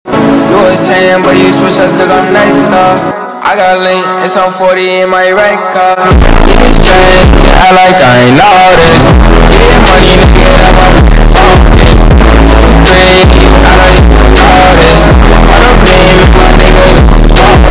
Noticed Bass Boosted Download for Android